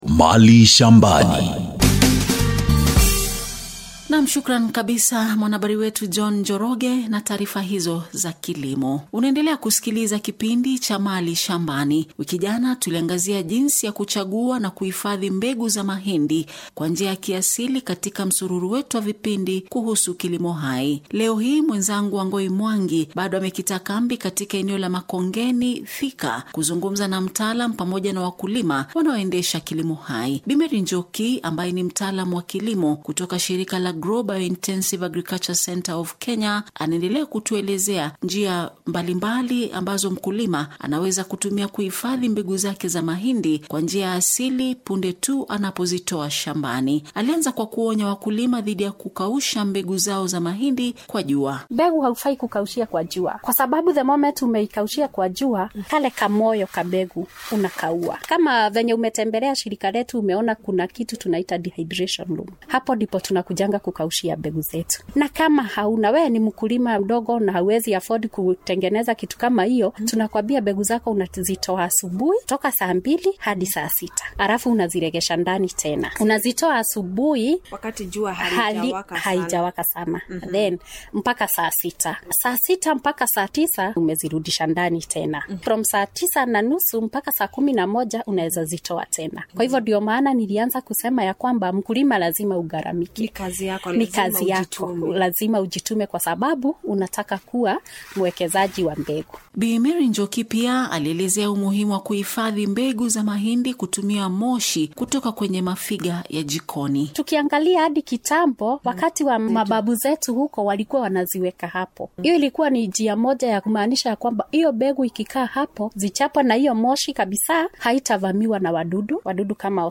The focus on preserving indigenous seeds and the partnership between GROW BIOINTENSIVE AGRICULTURE and KBC RADIO TAIFA for this important discussion.